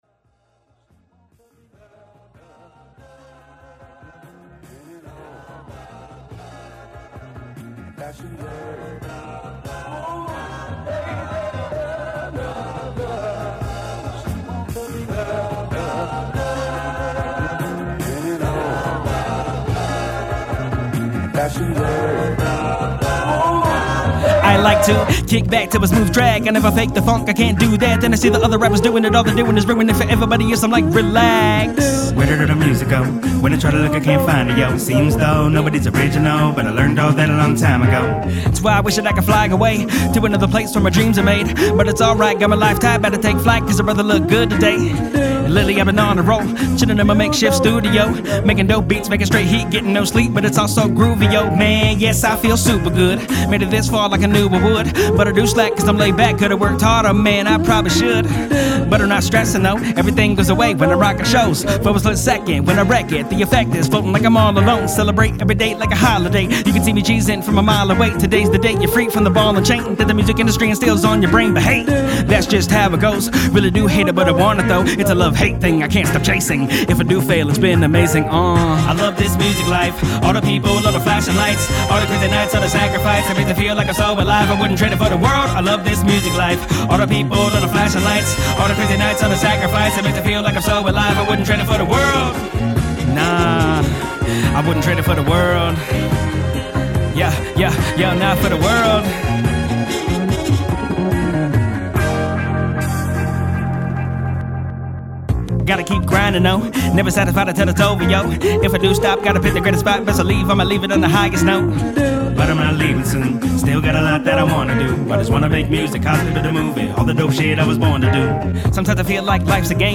Hiphop
Description : new found boombap/soul with a running man twi